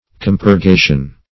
Compurgation \Com`pur*ga"tion\, n. [L. compurgatio, fr.